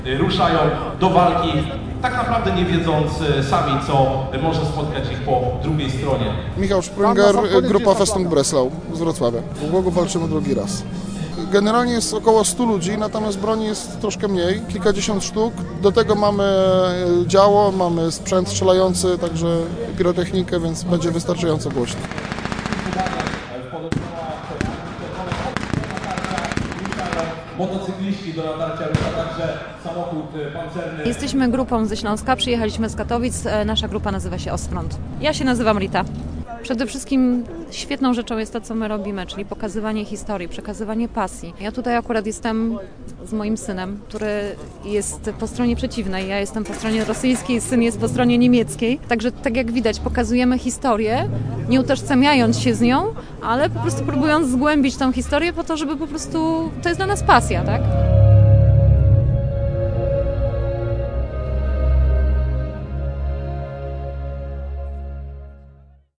Widowisko militarne